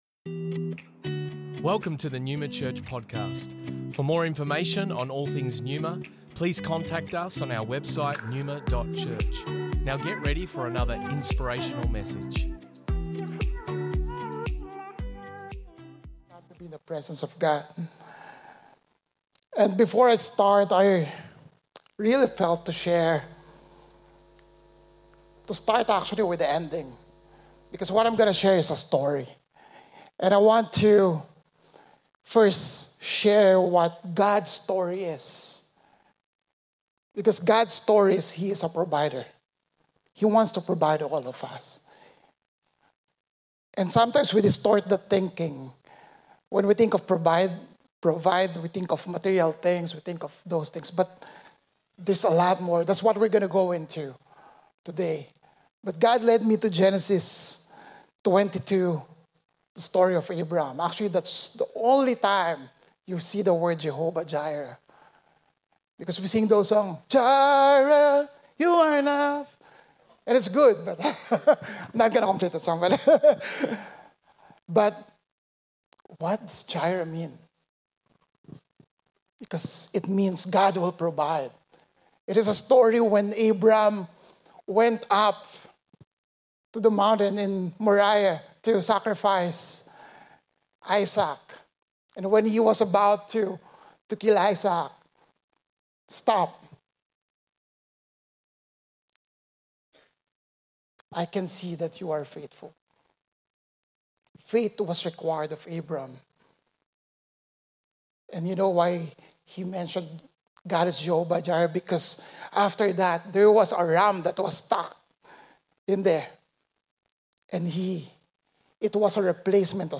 Originally recorded at Neuma Melbourne West 1st October 2023